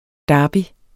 Udtale [ ˈdɑːbi ]